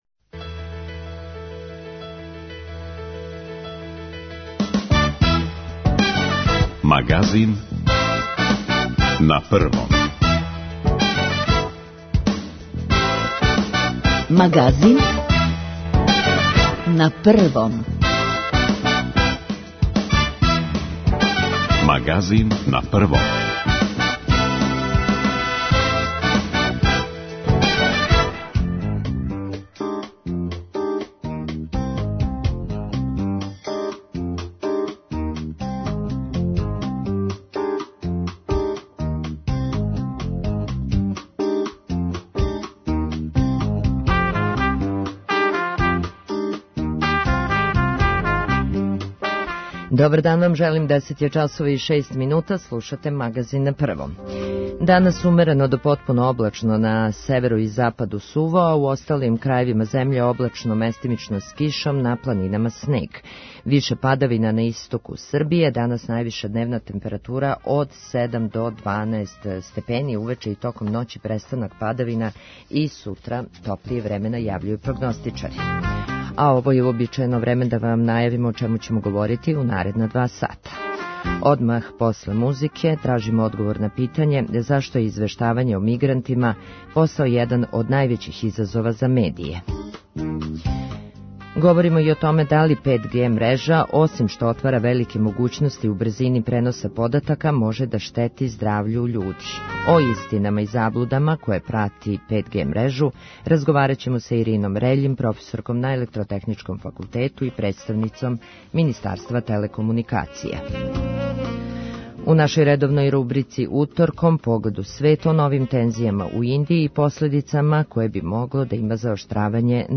Телефоном ћемо укључити Владимира Цуцића, комесара за избеглице и миграције.